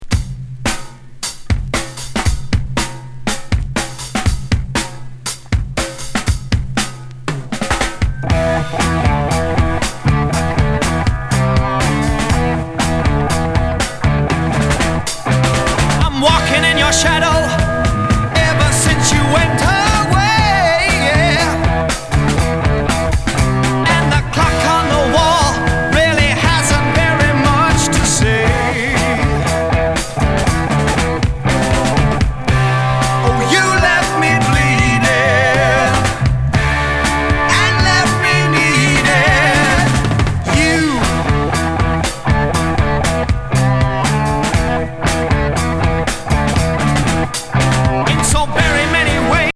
US盤JKTのヘビー・サイケロック1ST!イントロ・ドラム・ブレイク～ヘビー・ブギー